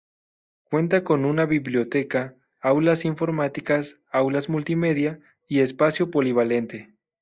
/polibaˈlente/